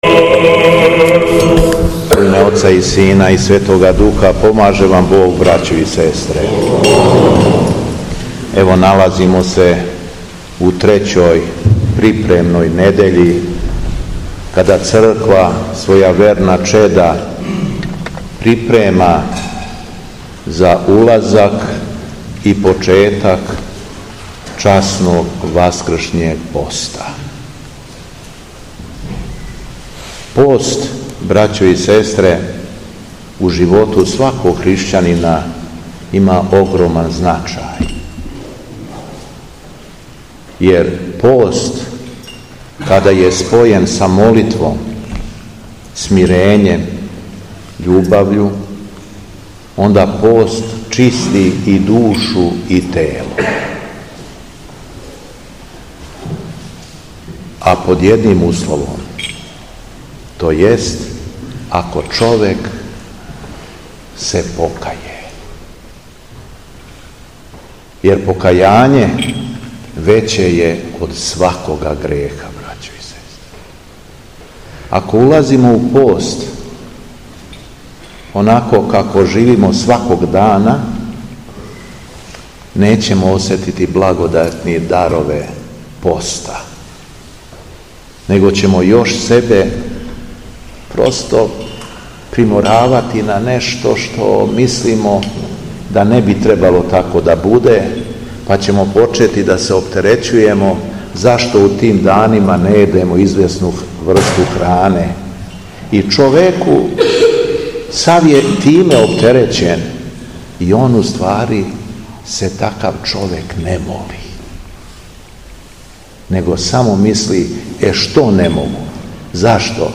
Беседа Његовог Високопреосвештенства Митрополита шумадијског г. Јована
Након прочитаног јеванђеља Митрополит се сабраним верницима обратио беседом: